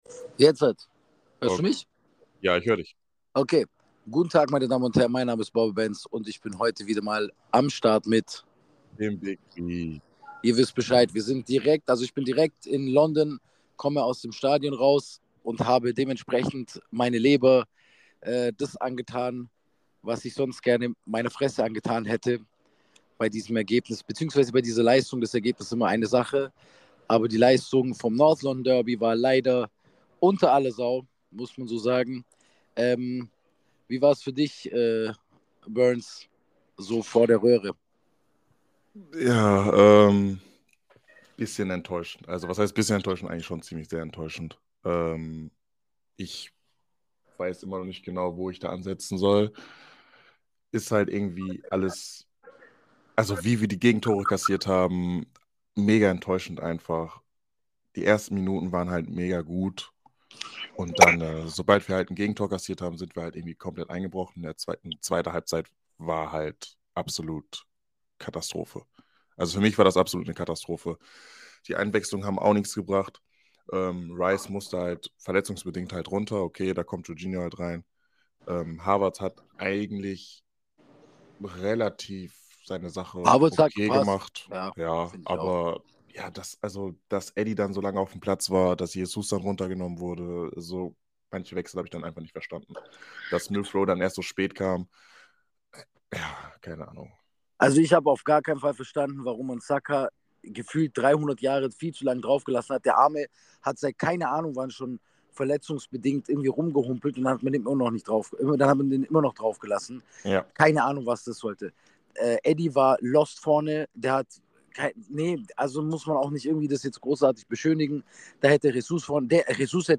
Man verzeihe uns die Qualität. Direkt nach dem Spiel vor dem Stadion.